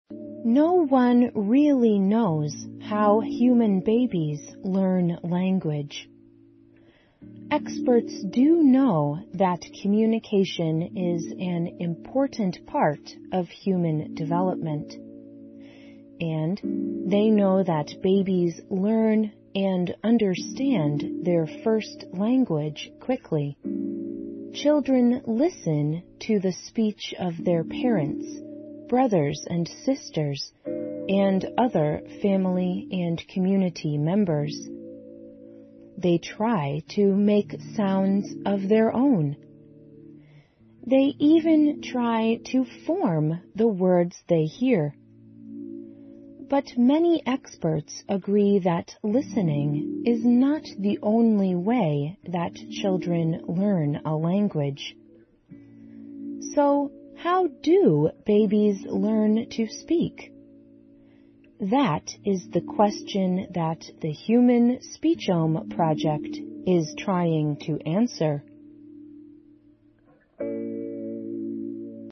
环球慢速英语 第553期:人类家庭语言计划(4)